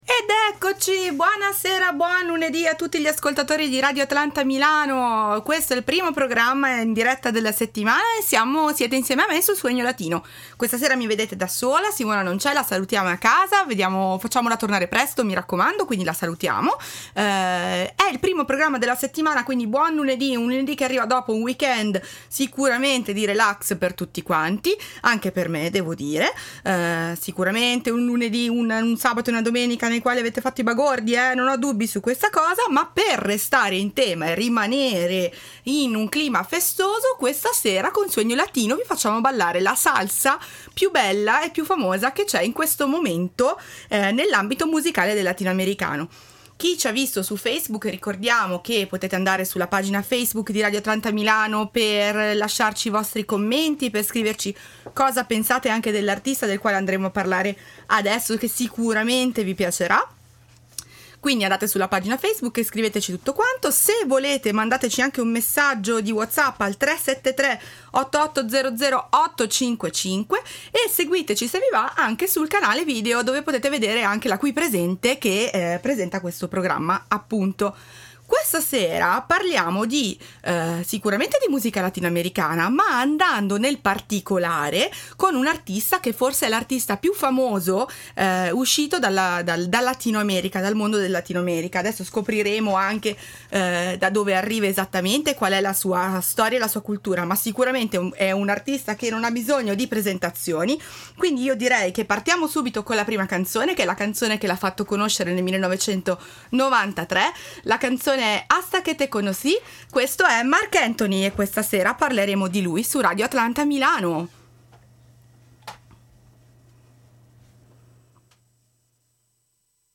Un appuntamento ogni settimana con un’esperta ed una profana della cultura latina che insieme andranno alla scoperta degli stili, delle tradizioni e dei grandi nomi della musica più caliente del mondo.